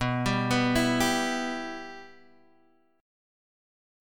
Bsus4#5 Chord